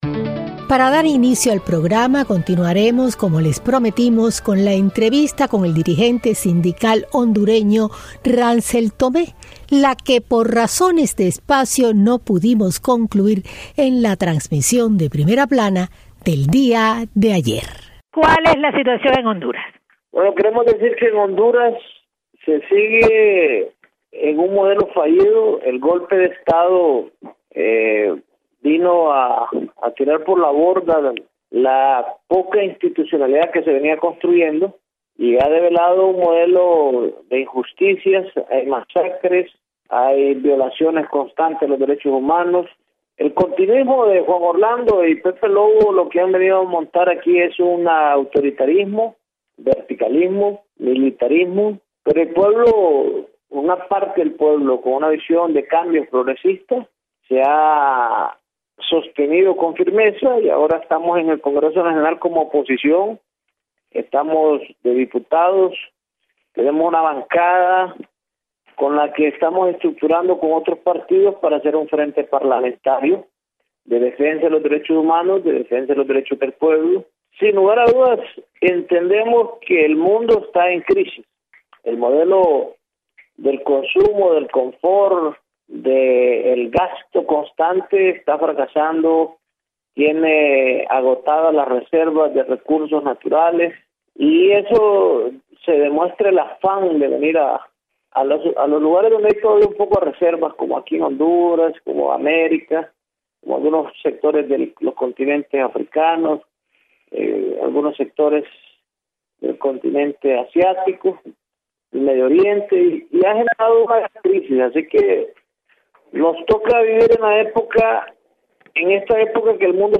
Ademas, la situacion electoral en Honduras a traves de una entrevista a un candidato presidencial./